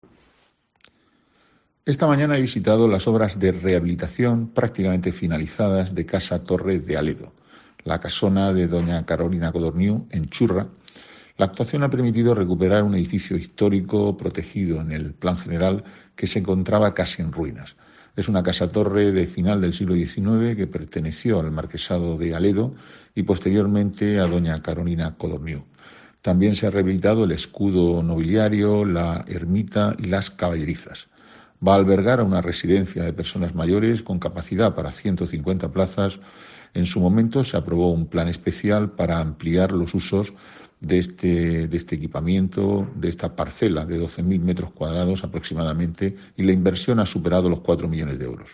Antonio Navarro Corchón, concejal de Planificación Urbanística, Huerta y Medio Ambiente